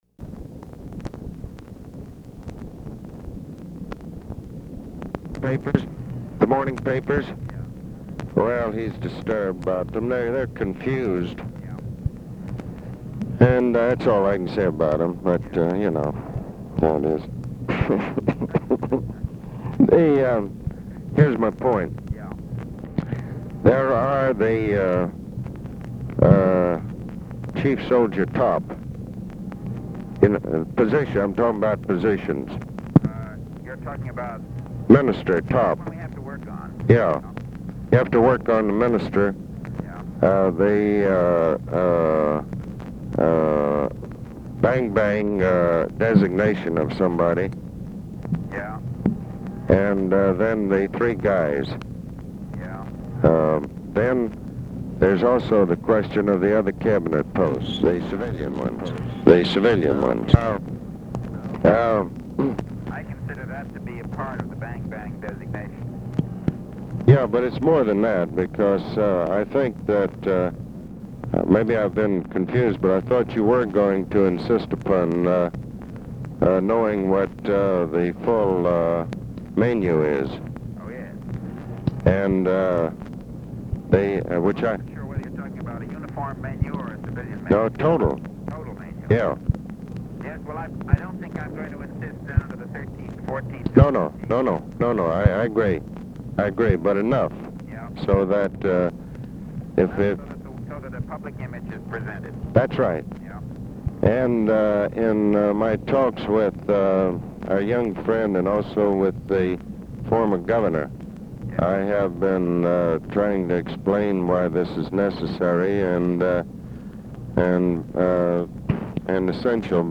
Conversation with ABE FORTAS
Secret White House Tapes